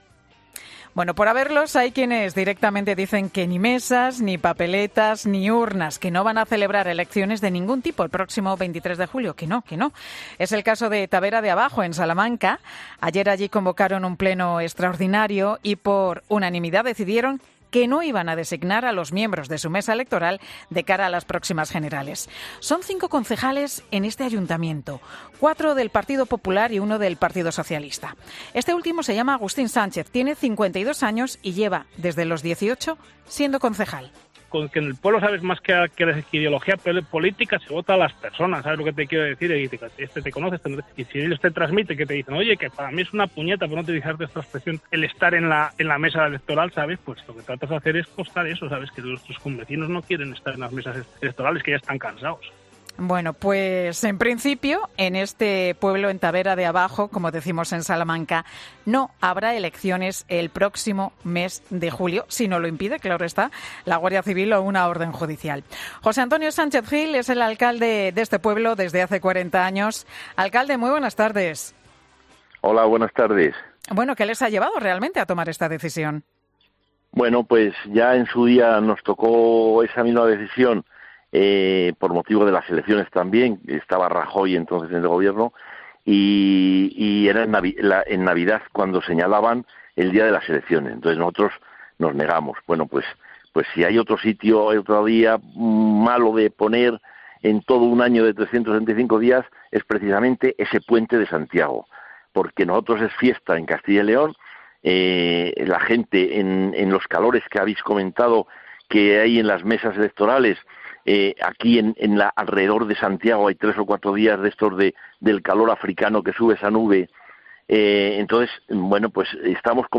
José Antonio Sánchez, alcalde de Tabera de Abajo, en Mediodía COPE